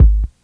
Kick 13.wav